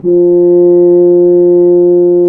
BRS F HRN 09.wav